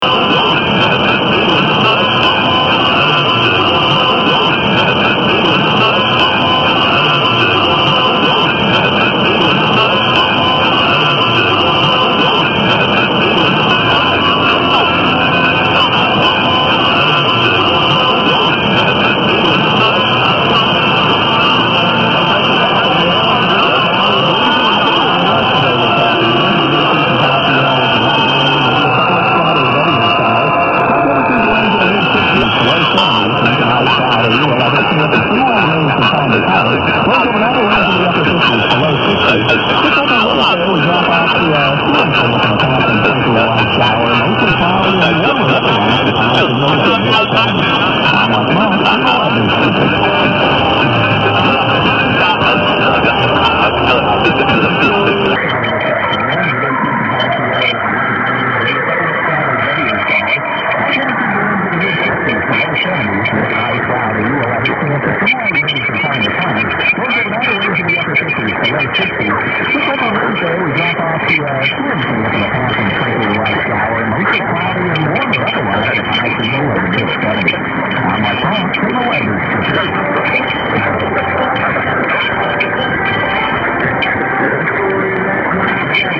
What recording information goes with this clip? The clip below of 12th is pretty poor, and the ID is repeated 4 times. 111012_0700_1370_wtab_very_poor_id_4_times_then_wx_lsb_then_usb.mp3